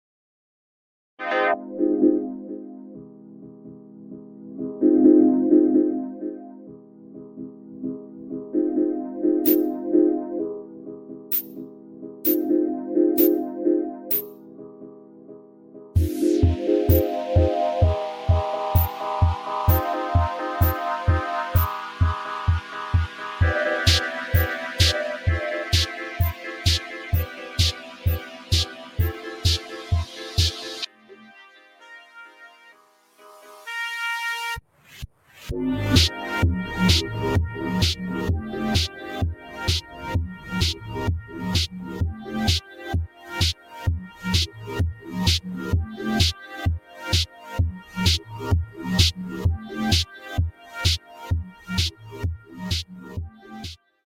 Mellow Melodies Soothing Sounds for